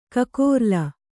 ♪ kakōrla